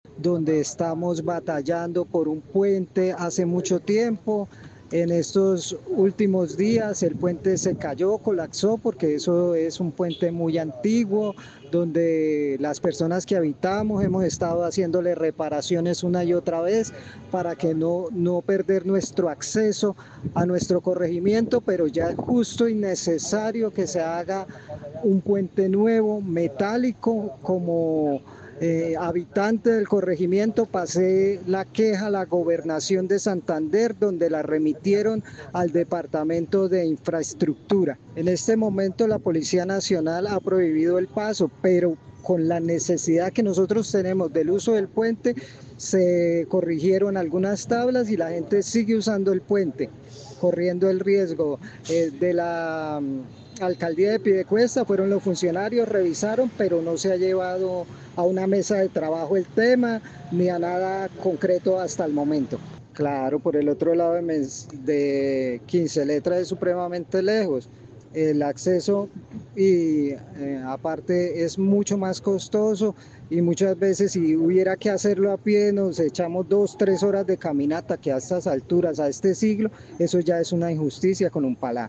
habitante de la zona